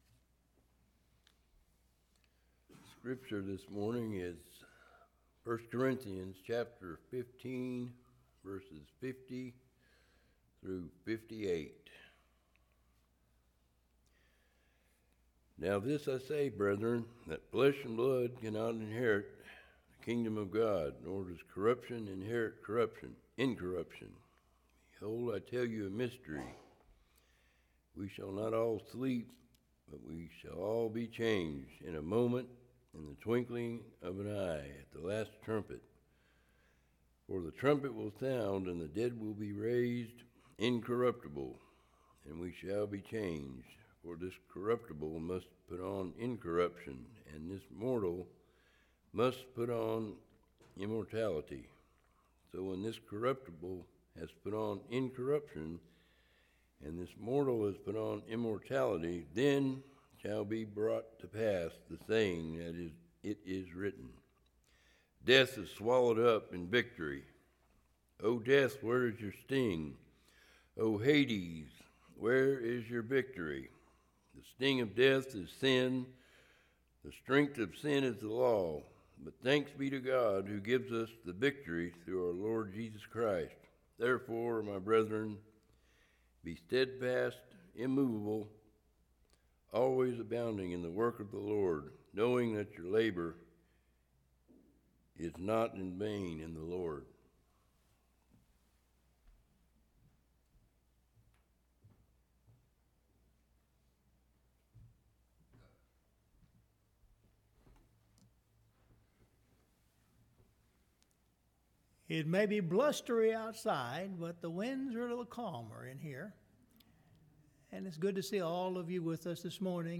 Scripture Reading – 1 Corinthians 15:50-58